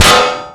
Cool metal clang sounds
metal sounds since it won’t let me public them: